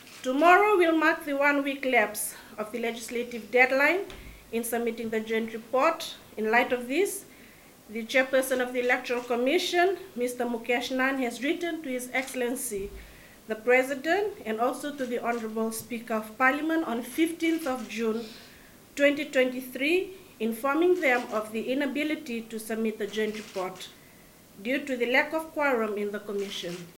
In a press conference this afternoon, Mataiciwa says the Electoral Commission is unable to meet because it has only two existing members, while five other positions are vacant.